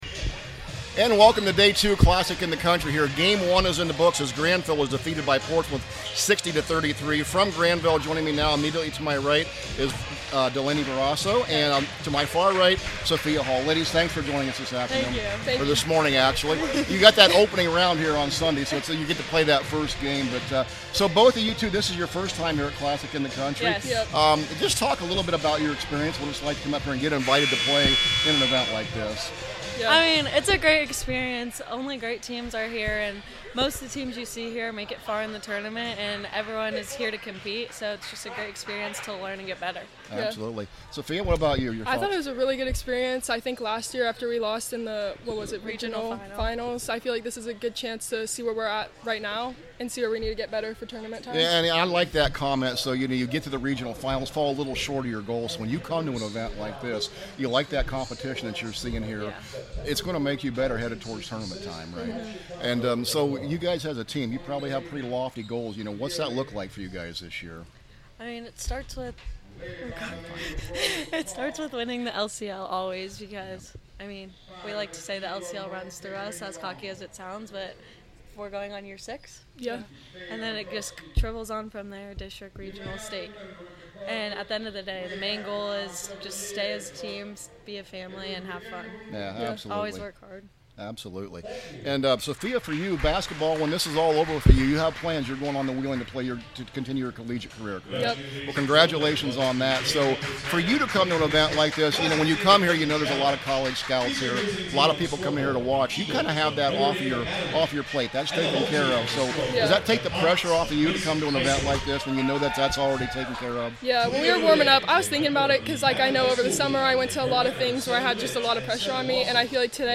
CLASSIC 2026 – GRANVILLE PLAYERS INTERVIEW